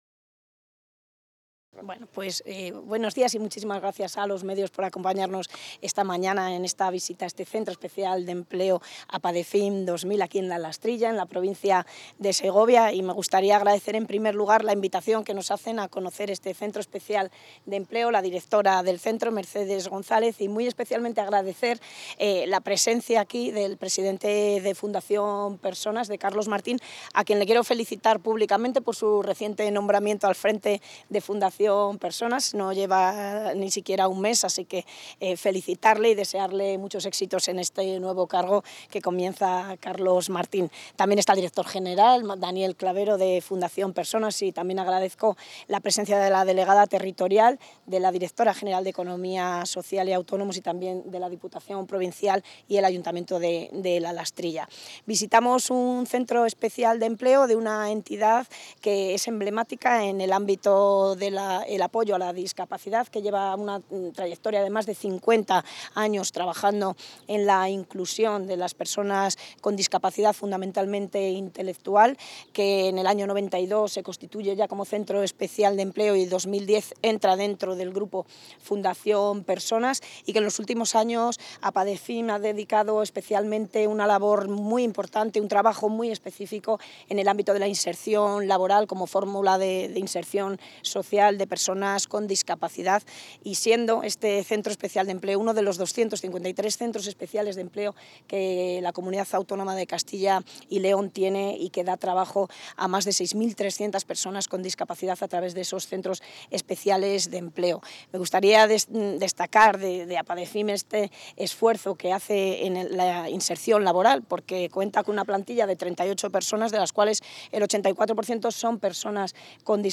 Intervención del consejero.
La consejera de Industria, Comercio y Empleo, Leticia García, ha visitado hoy en la localidad segoviana de La Lastrilla el Centro Especial de Empleo APADEFIM 2000, donde ha destacado la apuesta de la Junta de Castilla y León por estas entidades de la economía social que constituyen un instrumento esencial para la inclusión social de personas con discapacidad mediante la creación de empleo.